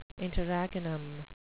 interregnum (in-tuhr-REG-nuhm) noun
Pronunciation: